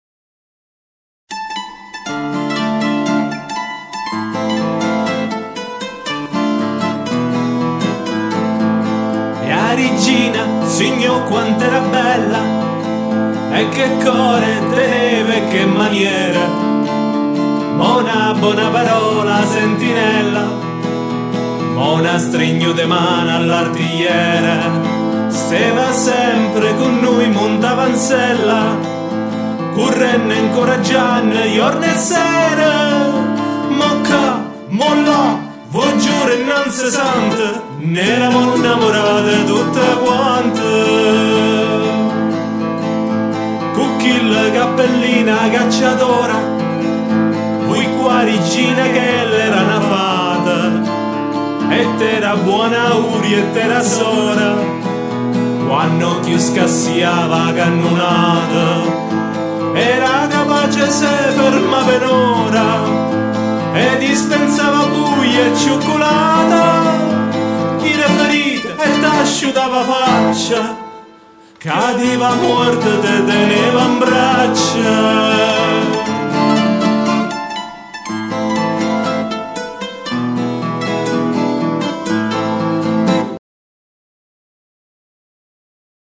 Un soldato intona una canzone in onore di Mara Sofia